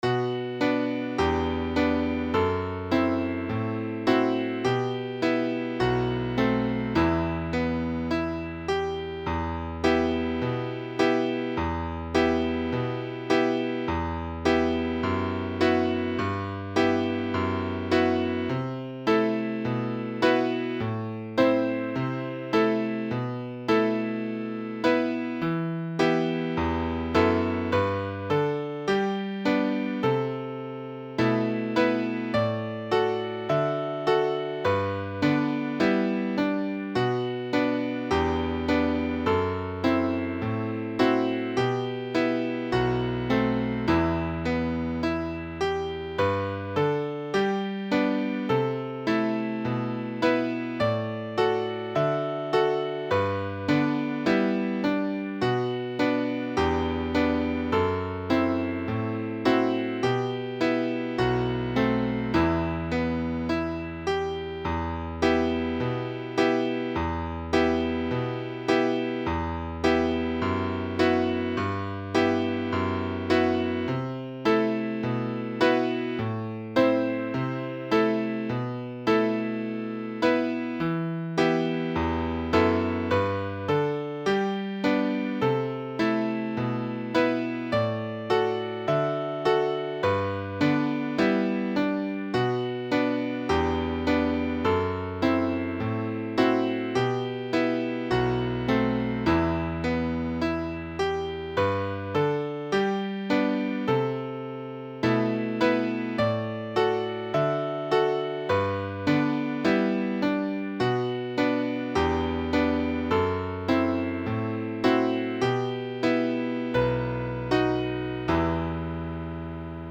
скрипка , қобыз